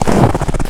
High Quality Footsteps
STEPS Snow, Walk 25-dithered.wav